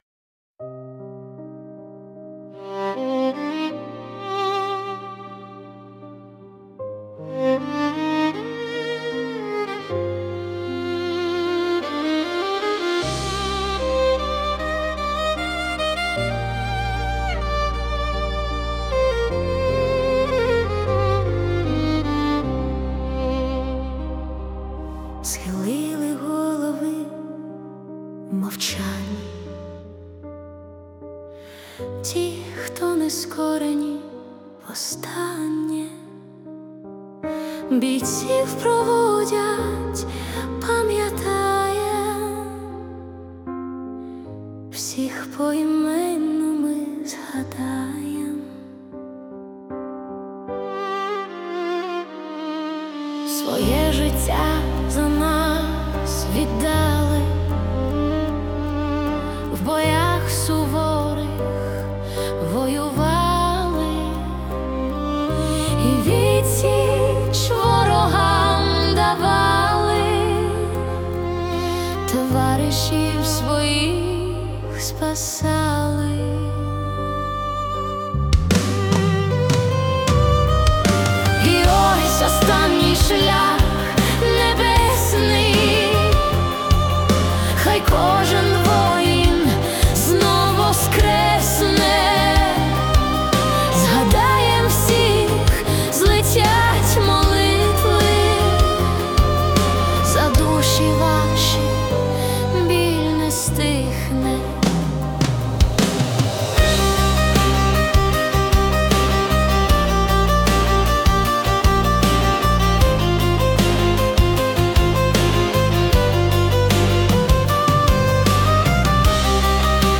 Monologue / Requiem